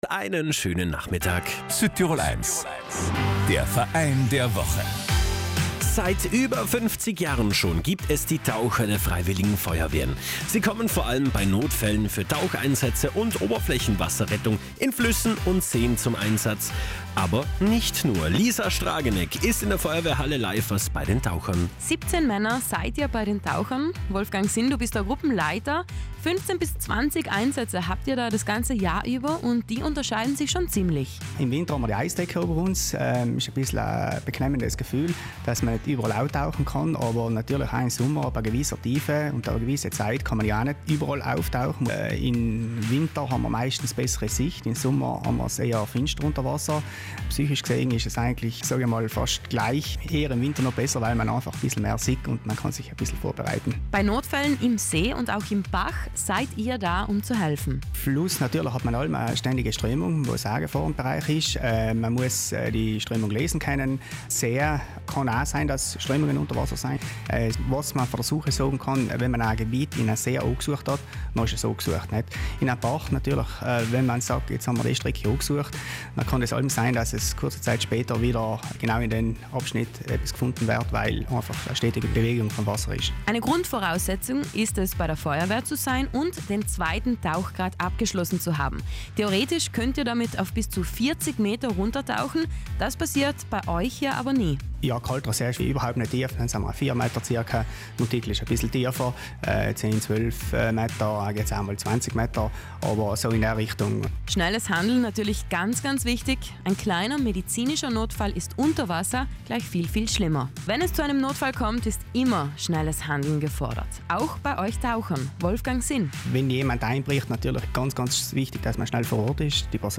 Wie so ein Einsatz abläuft und was die Taucher alles zur Ausrüstung zählen, haben sie uns erzählt.